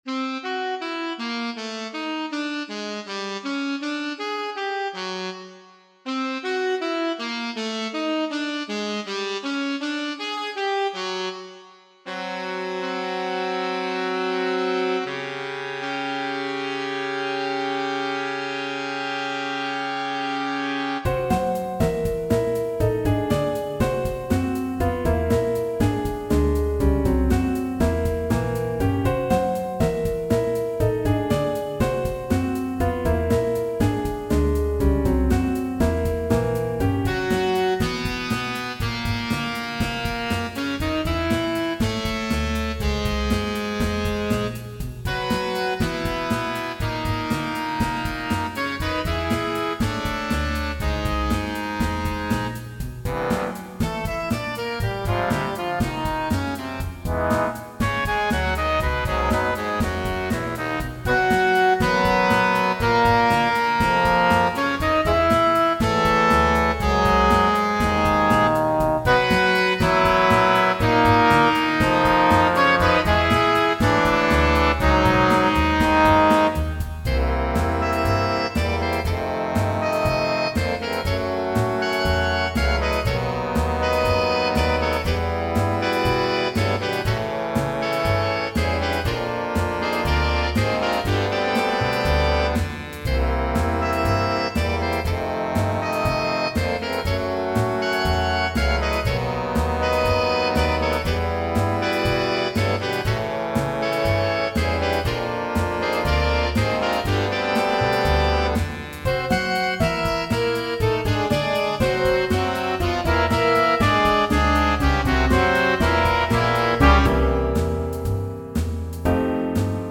Vocal, Trumpet, Sax, Trombone, Piano, Bass, Drums
All audio files are computer-generated.